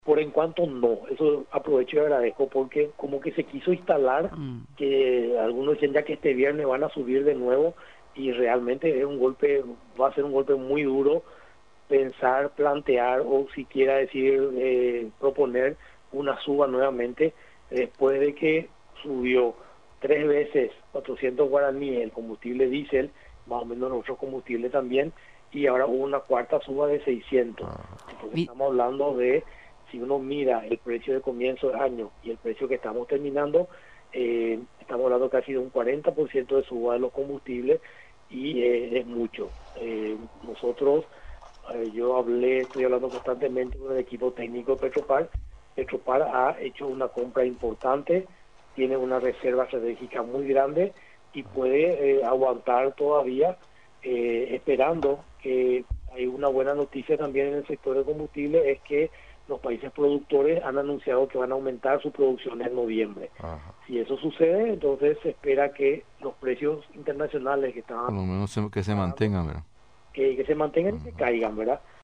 Los precios del combustible se mantendrán, aseguró este miércoles el viceministro de Comercio y Servicios, Pedro Mancuello.